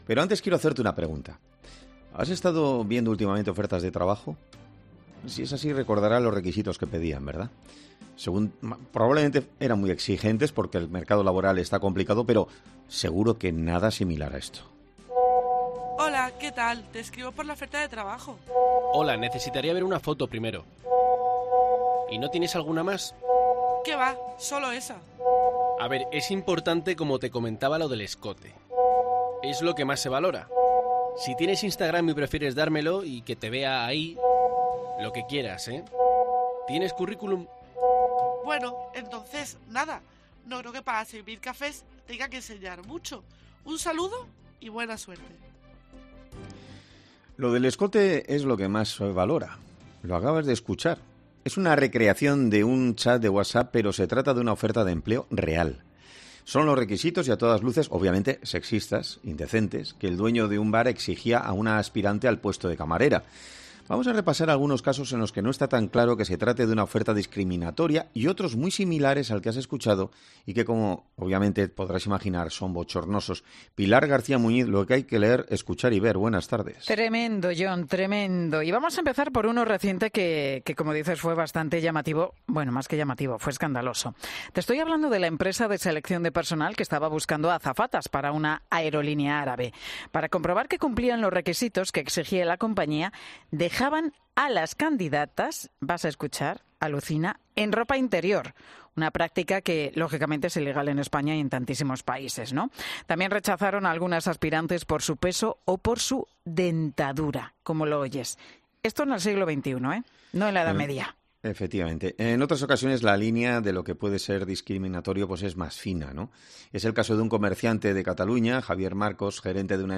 Habla un gerente, afectado por la multa que le han impuesto a causa de un anuncio publicado